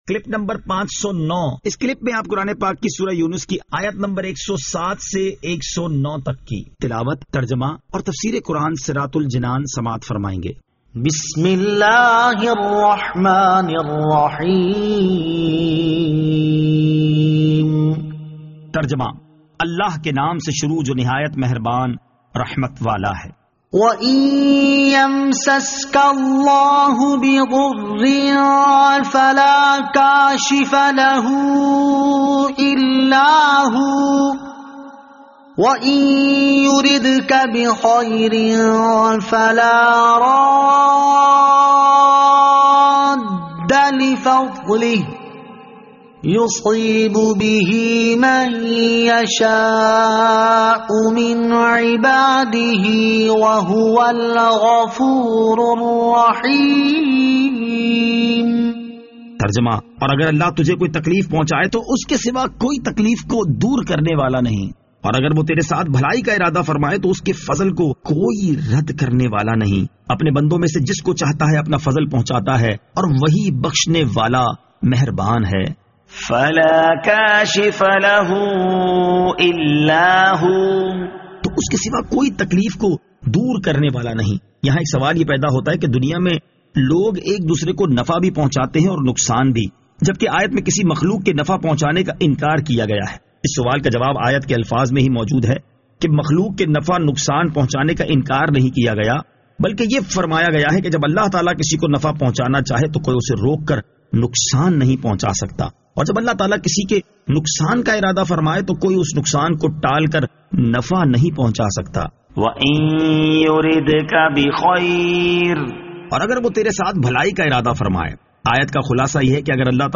Surah Yunus Ayat 107 To 109 Tilawat , Tarjama , Tafseer
2021 MP3 MP4 MP4 Share سُوَّرۃُ یُونُس آیت 107 تا 109 تلاوت ، ترجمہ ، تفسیر ۔